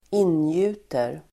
Ladda ner uttalet
ingjuta verb, inspire , infuse Grammatikkommentar: A & x i (B) Uttal: [²'in:ju:ter] Böjningar: ingöt, ingjutit, ingjut, ingjuta, in|gjuter Definition: inge Exempel: ingjuta mod (inspire with courage)
ingjuter.mp3